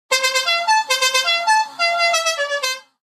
Download Truck Horn sound effect for free.
Truck Horn